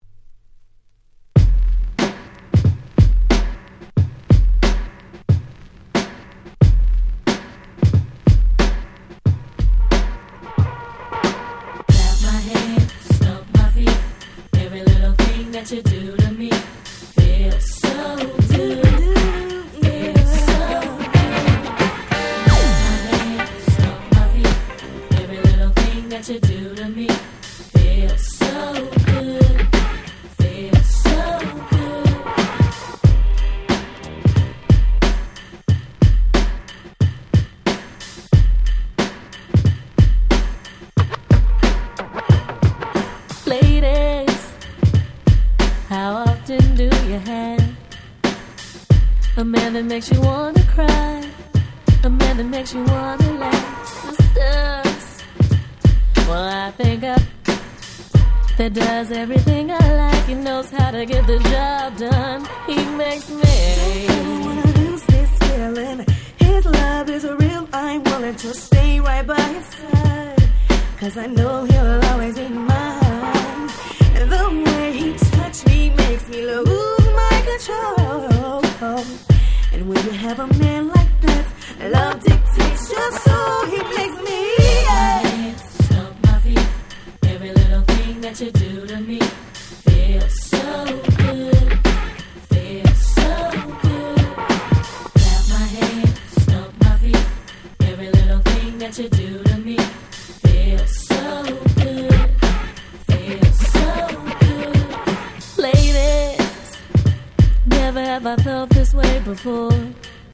95' Smash Hit R&B !!
決して派手さは無いものの、彼女達のコーラスワークを十二分に堪能出来る素晴らしいHip Hop Soul